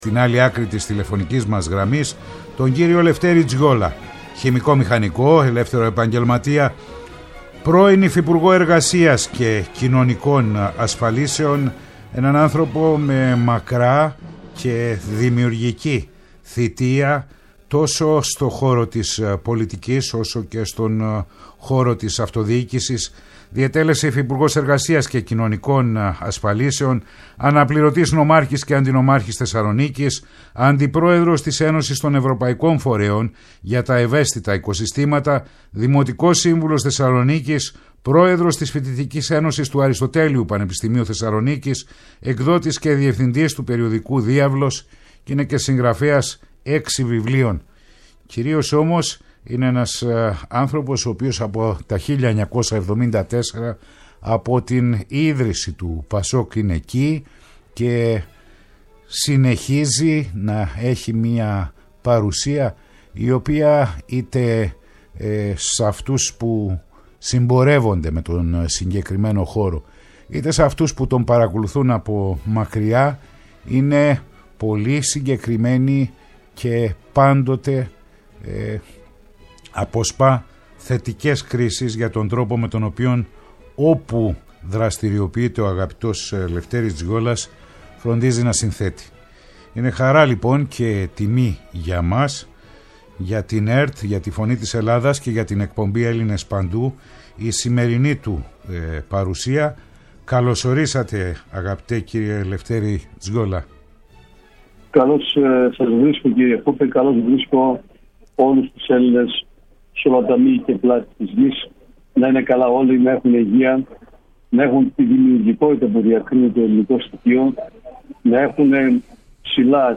Η ΦΩΝΗ ΤΗΣ ΕΛΛΑΔΑΣ Ελληνες Παντου ΣΥΝΕΝΤΕΥΞΕΙΣ Συνεντεύξεις